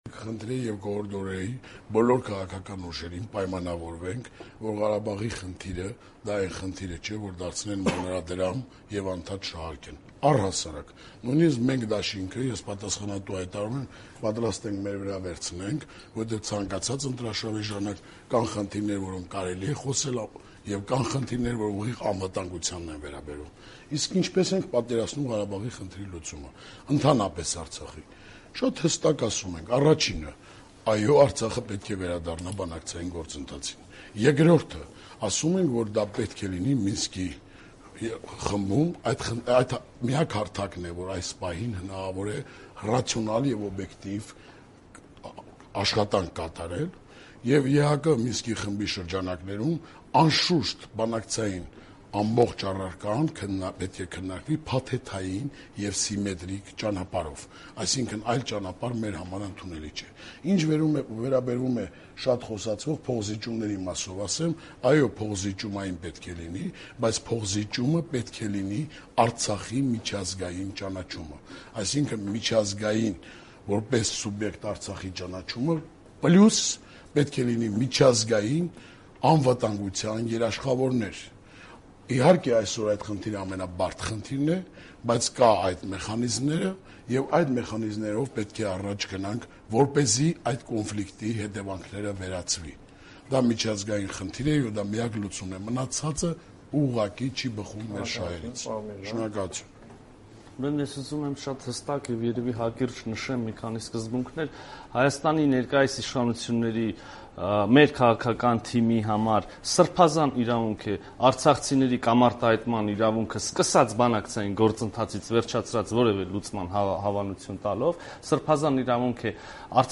Ղարաբաղյան խնդիր. բանավեճ «Ազատության» հեռուստաեթերում